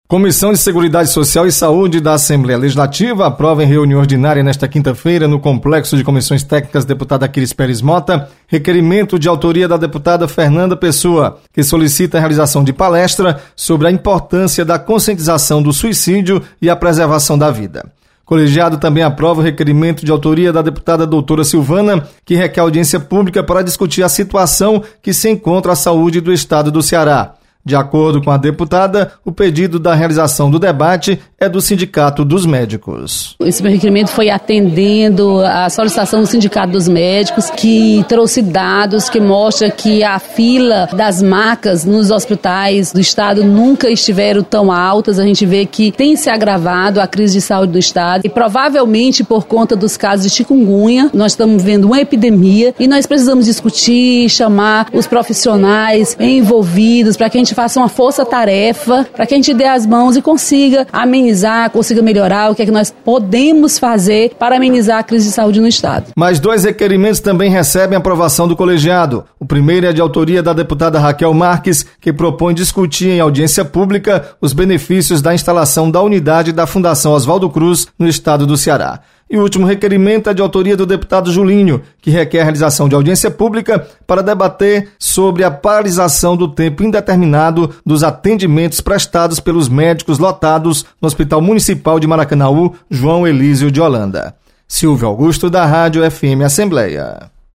Você está aqui: Início Comunicação Rádio FM Assembleia Notícias Comissão